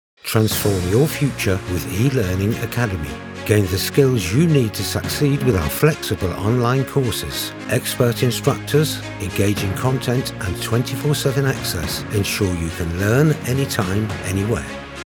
British Radio, TV Commercial Voice Overs Talent
Adult (30-50) | Older Sound (50+)